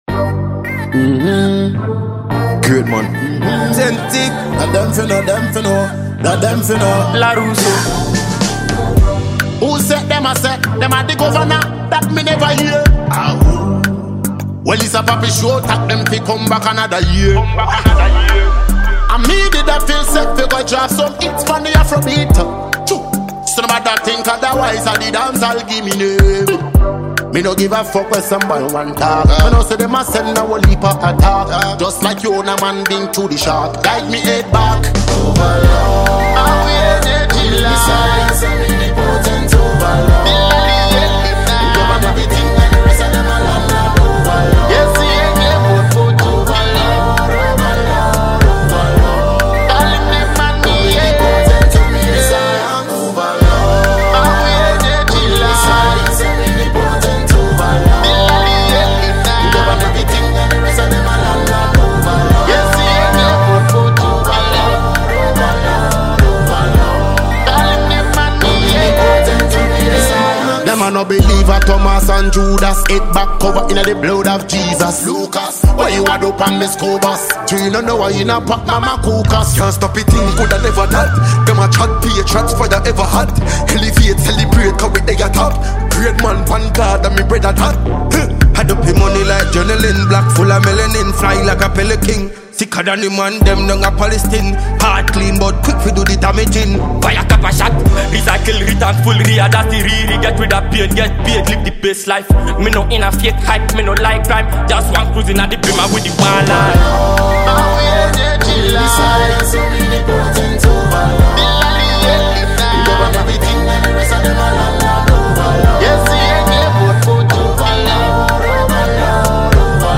Ghanaian dancehall musician and awarding songwriter